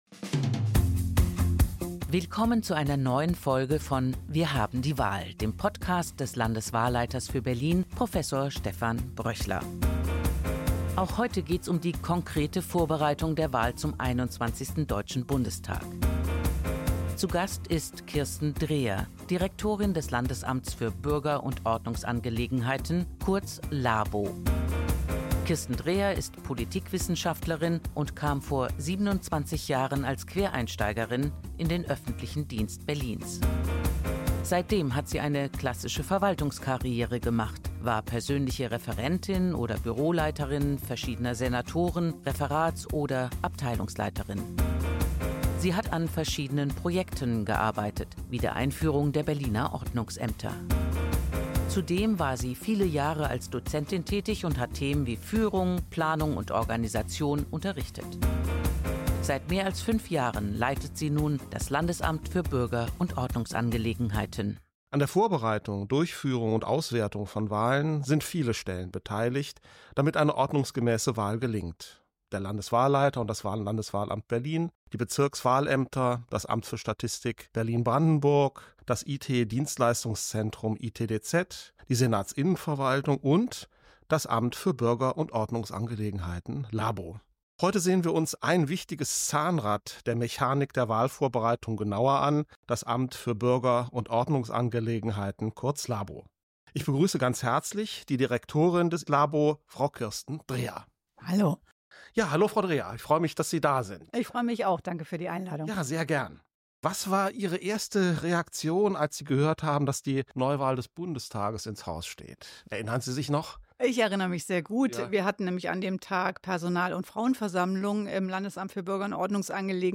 Beschreibung vor 1 Jahr In Folge 10 des Podcast „Wir haben die Wahl“ spricht Landeswahlleiter Prof. Dr. Stephan Bröchler mit Kirsten Dreher, Direktorin des Landesamts für Bürger- und Ordnungsangelegenheiten (LABO), über die wichtige Rolle des LABO bei der Vorbereitung der vorgezogenen Wahl zum 21. Deutschen Bundestag. Kirsten Dreher, die seit mehr als 27 Jahren im öffentlichen Dienst tätig ist, gewährt spannende Einblicke in die komplexen Prozesse hinter den Kulissen.